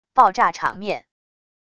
爆炸场面wav音频